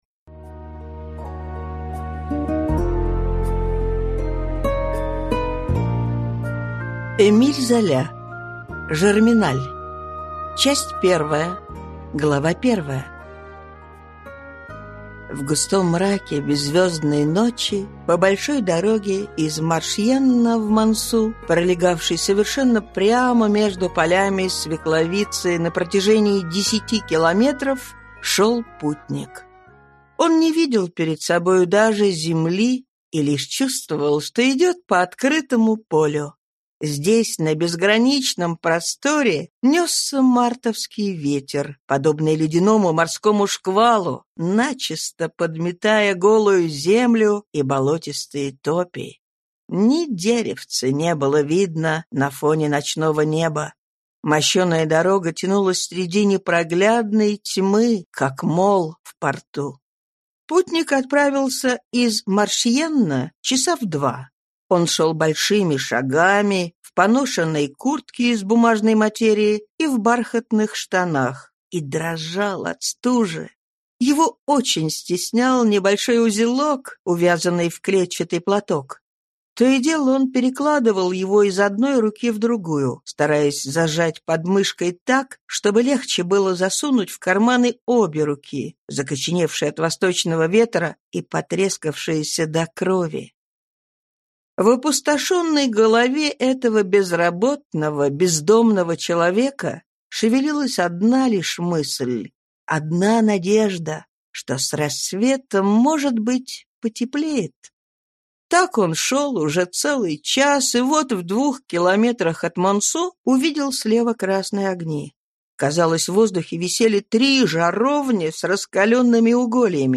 Аудиокнига Жерминаль | Библиотека аудиокниг